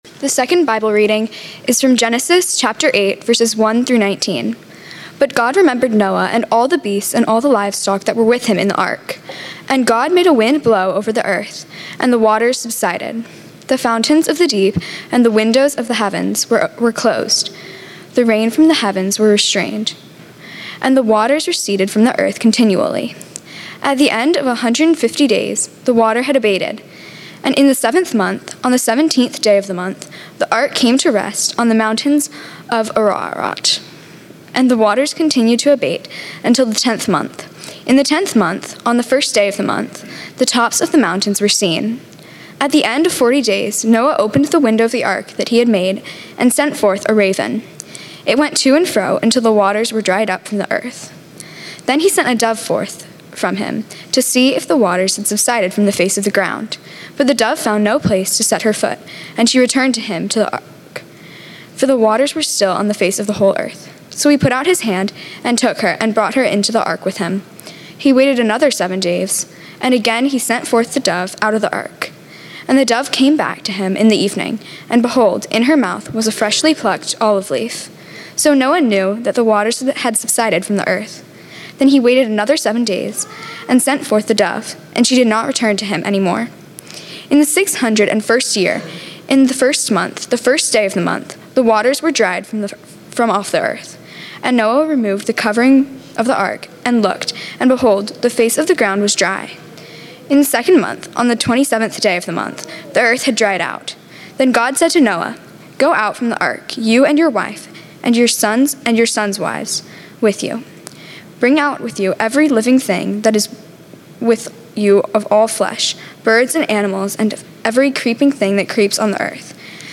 BDMV-sermon.mp3